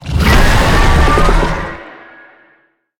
Sfx_creature_shadowleviathan_roar_aggro_01.ogg